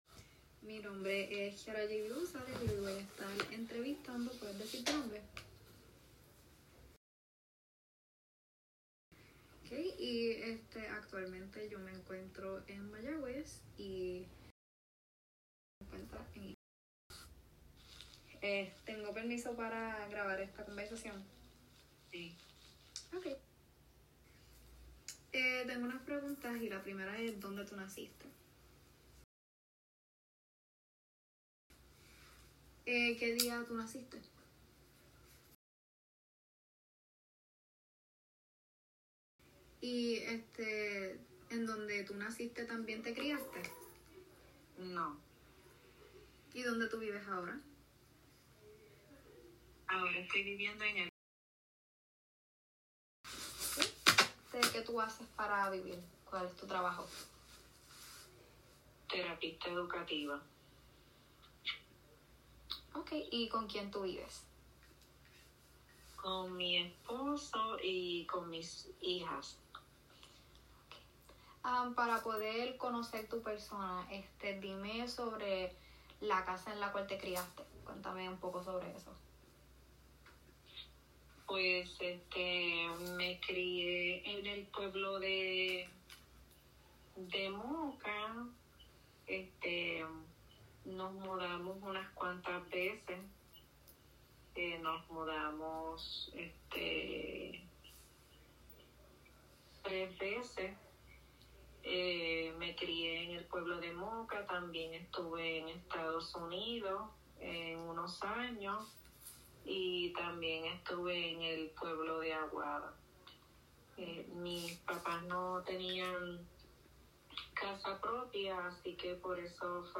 Oral history interview with anonymous person, September 12, 2024